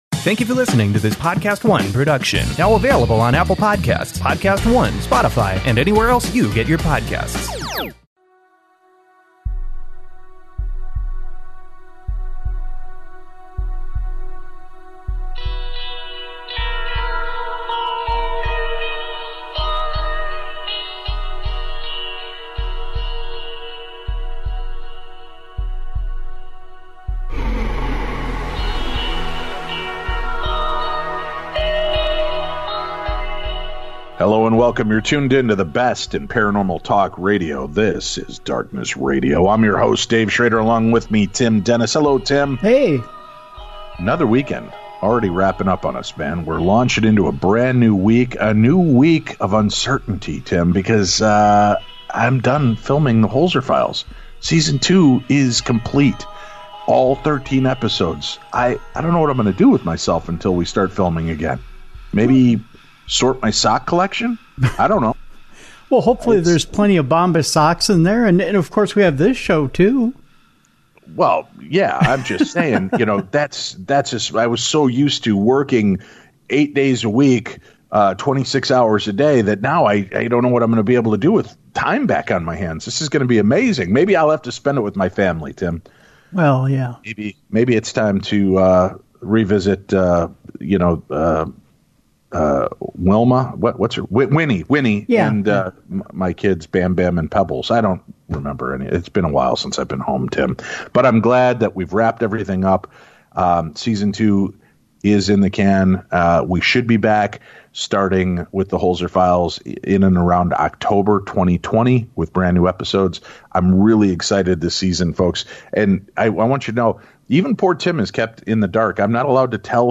You're tuned into the best in paranormal talk radio.